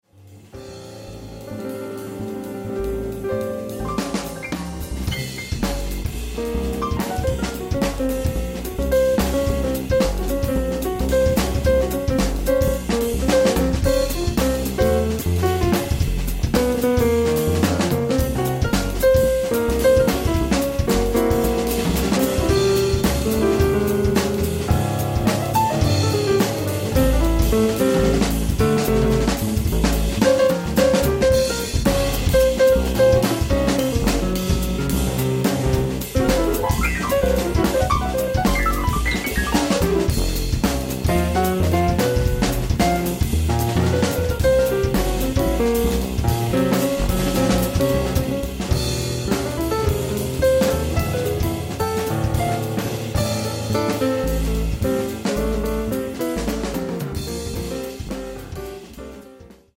piano
bass
drums
ritmata e riflessiva